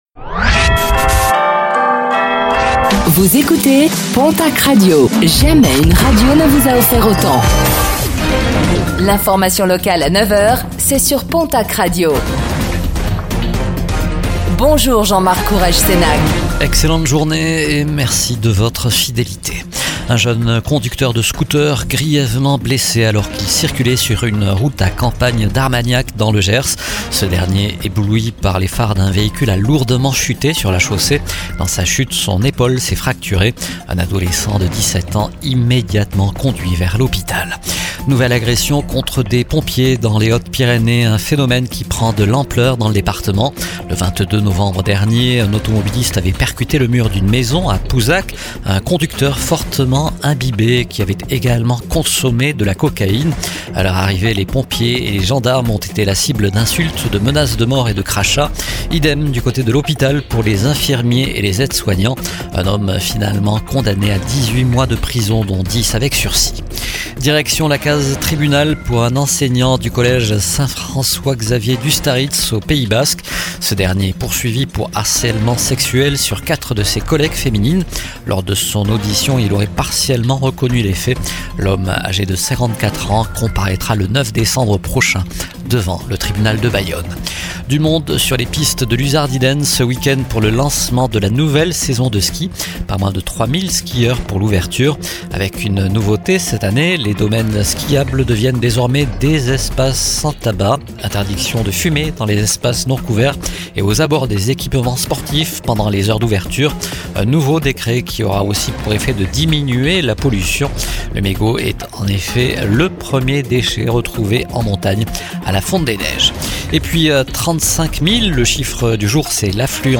Réécoutez le flash d'information locale de ce lundi 1er décembre 2025